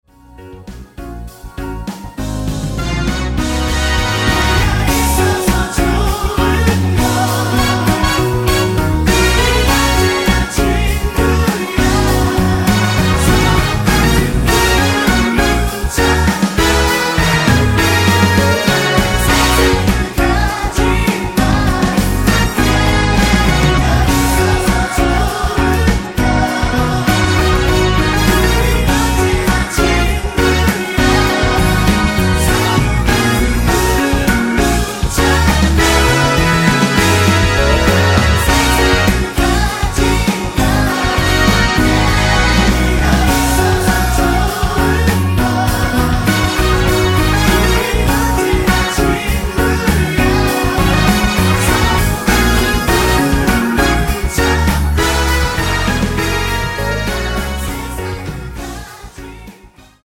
원곡 3분 지나서 부터 나오는 코러스 포함된 MR입니다.(미리듣기 확인)
원키에서(+1)올린 코러스 포함된 MR입니다.
F#
앞부분30초, 뒷부분30초씩 편집해서 올려 드리고 있습니다.